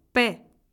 En cliquant sur le symbole, vous entendrez le nom de la lettre.
lettre-p.ogg